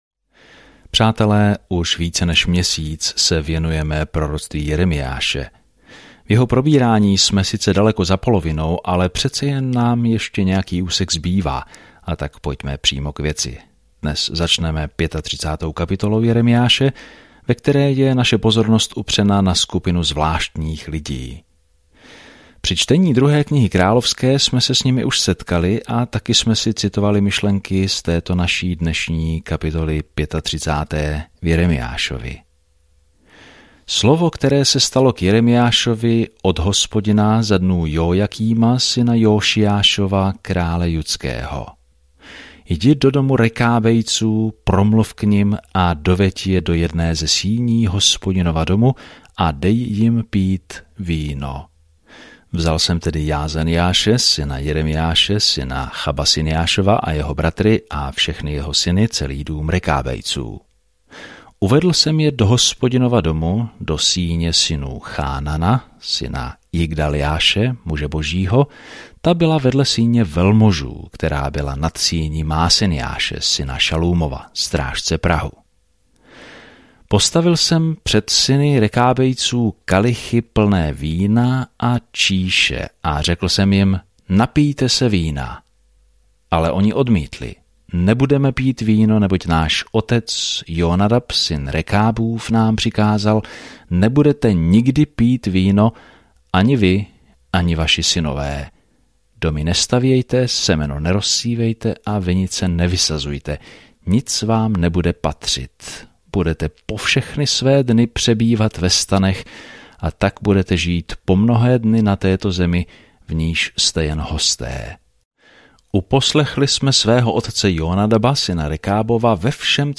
Písmo Jeremiáš 35 Jeremiáš 36 Den 22 Začít tento plán Den 24 O tomto plánu Bůh si vybral Jeremiáše, muže něžného srdce, aby předal drsné poselství, ale lidé toto poselství nepřijímají dobře. Denně procházejte Jeremiášem, poslouchejte audiostudii a čtěte vybrané verše z Božího slova.